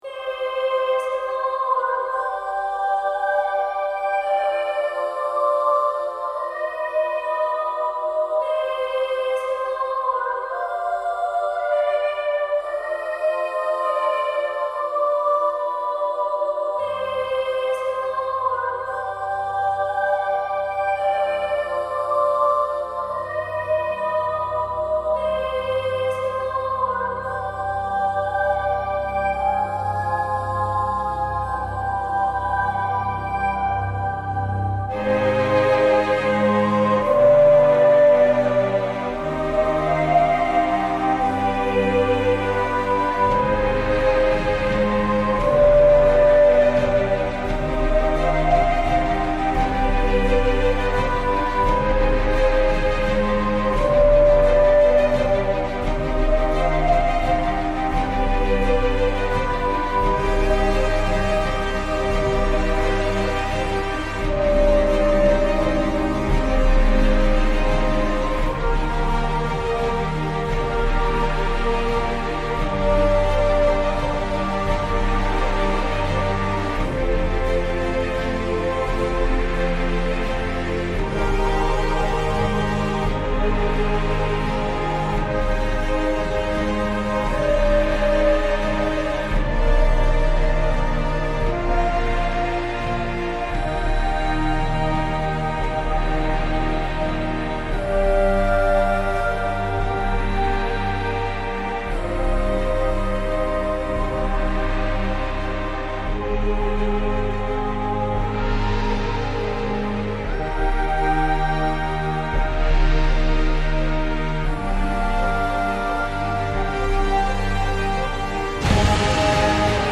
MHAwQQFloHs_goddess-of-love-epic-music-ancient-gods.mp3